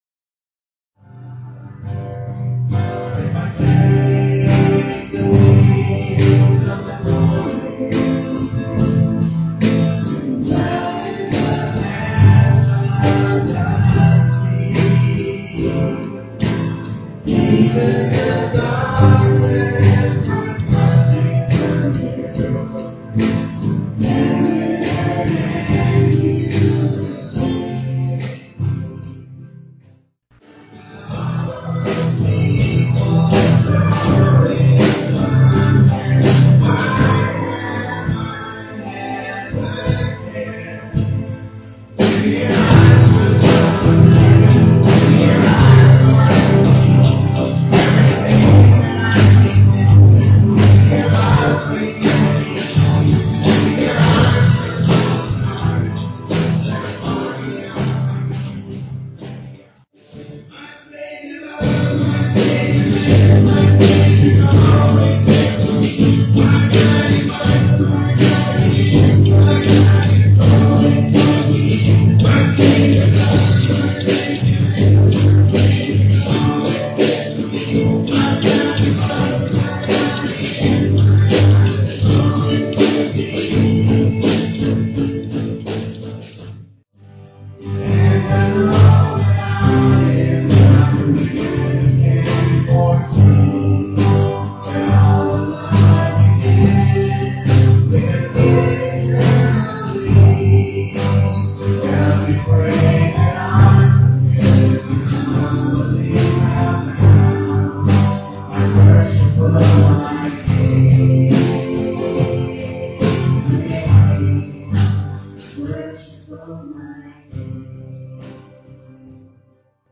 EBBC Worship Band